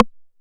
TR 909 Rimshot.wav